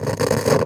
pgs/Assets/Audio/Electricity_Hums/radio_tv_electronic_static_07.wav at master
radio_tv_electronic_static_07.wav